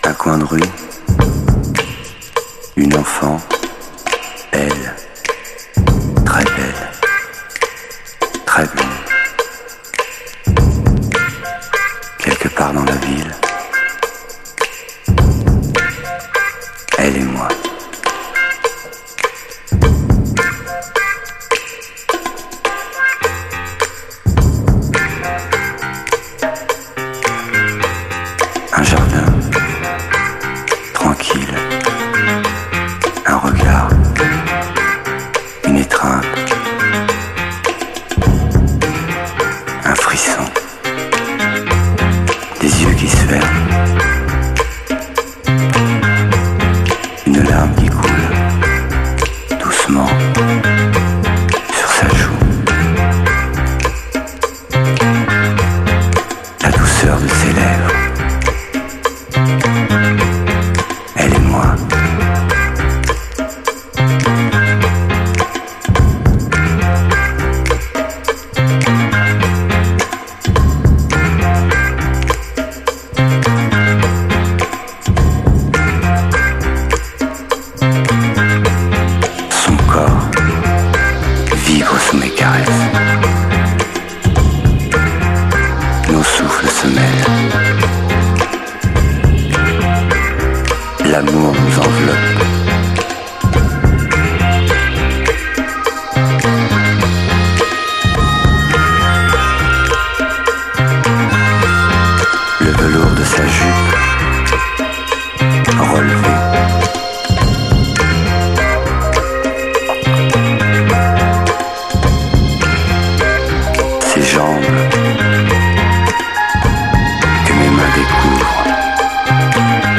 DISCO
レゲエっぽくもありレアグルーヴ的でもあります。